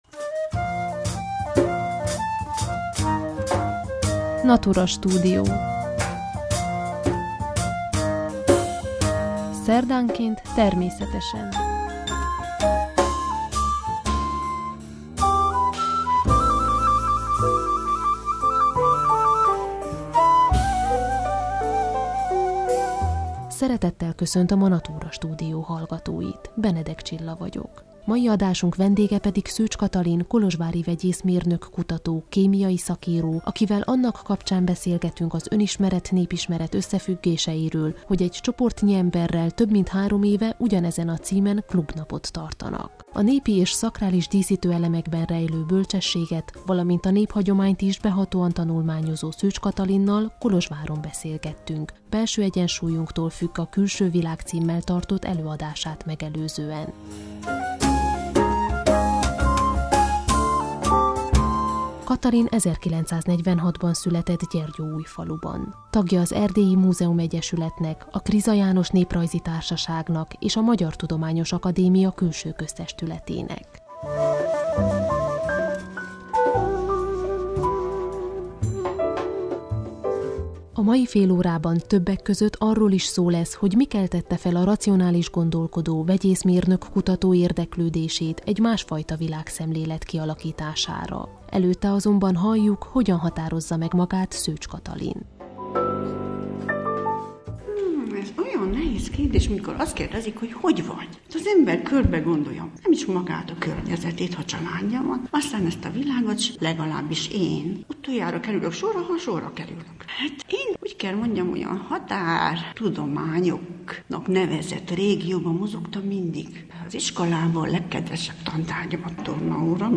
Egy csoportnyi emberrel, több mint három éve, Önismeret-népismeret címen klubnapot tartanak. Ennek kapcsán beszélgetünk vele.